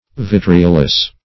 Vitriolous \Vi*tri"o*lous\, a.